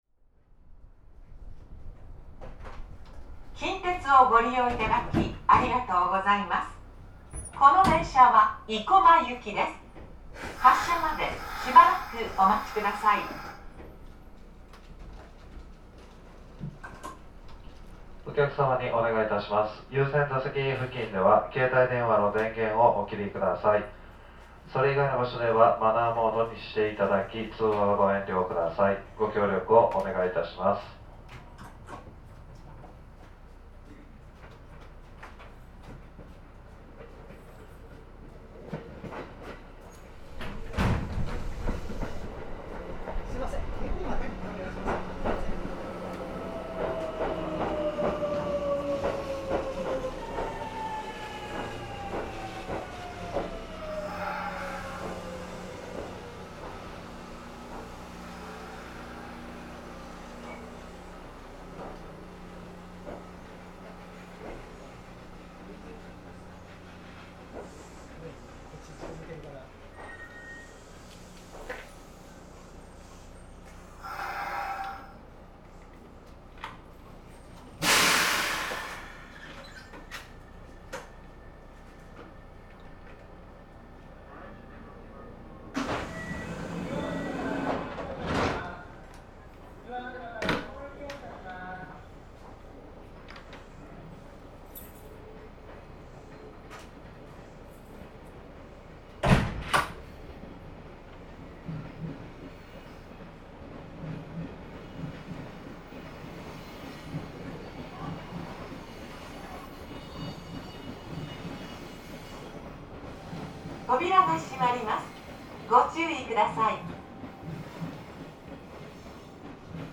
近畿日本鉄道 260系 ・ 走行音(全区間) (11.5MB*) 収録区間：内部・八王子線 四日市→西日野 制御方式：抵抗制御(吊掛駆動) 主電動機：MB-464AR(38kW) 1982(昭和57)年に登場した特殊狭軌(軌間762mm)用の車両。
足回りは270系と同じで、主電動機装架スペースの関係から吊掛駆動となっており、特有の駆動音を立てる。台車の間隔の関係で、ジョイント音が一般的な鉄道とは異なる音となっている。